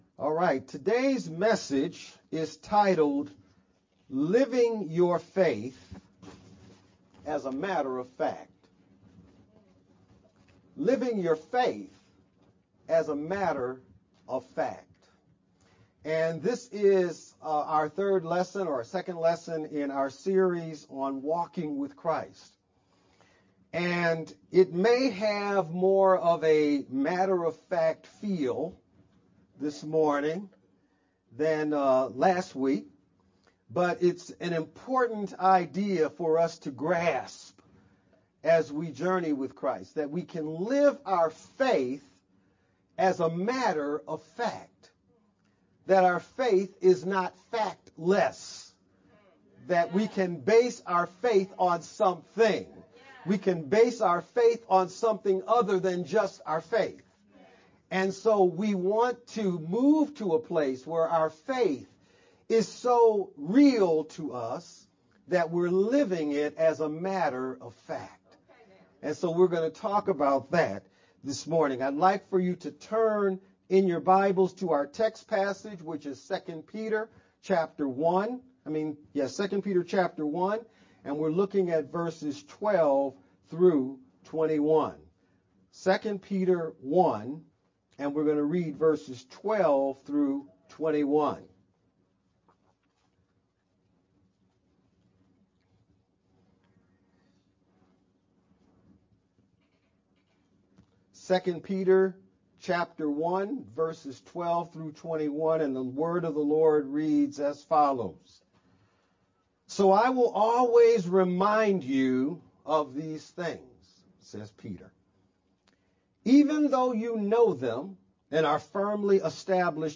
Oct-12th-VBCC-Sermon-only-Made-with-Clipchamp_Converted_Converted-CD.mp3